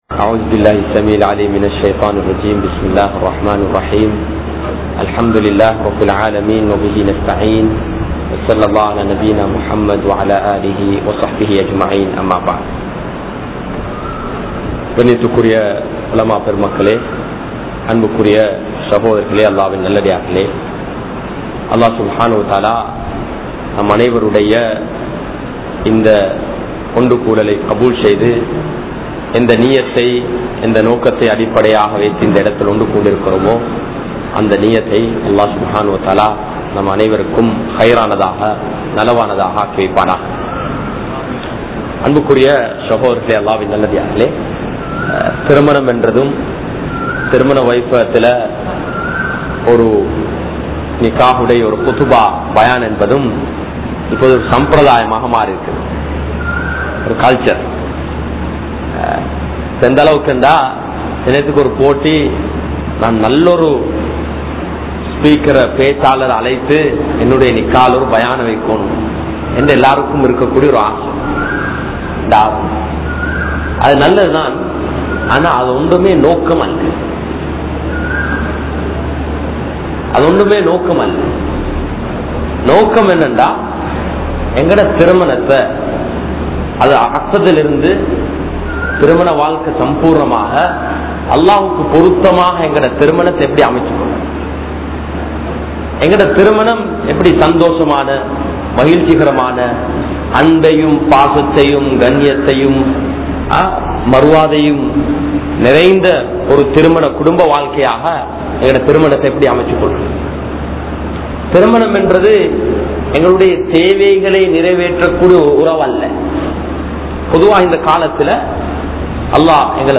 Thirumanaththai Padiungal (திருமணத்தை படியுங்கள்) | Audio Bayans | All Ceylon Muslim Youth Community | Addalaichenai